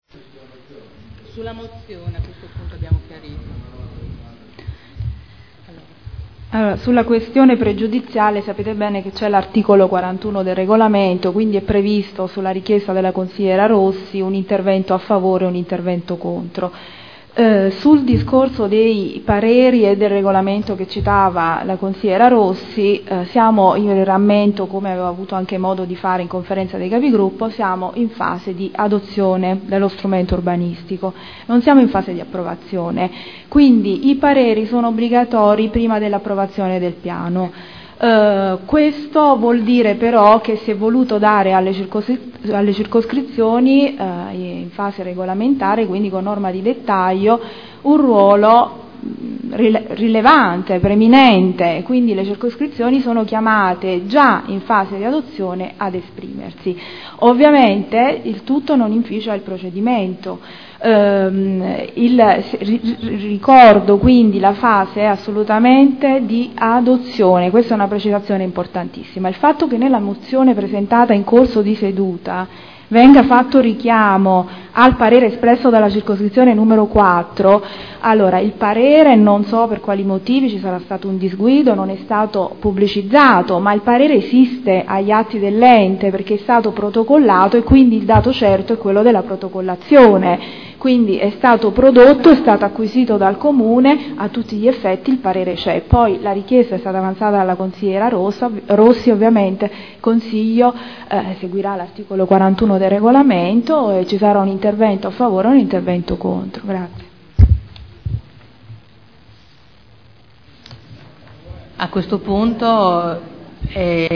Segretario — Sito Audio Consiglio Comunale
Seduta del 24/09/2012 Precisazione su regolamento durante dibattito du Delibera Zona elementare 280 Area 01 e Area 06 – Via Giardini – Variante al POC-RUE con valore ed effetti di Piano Urbanistico Attuativo (PUA) e Mozione prot. 111618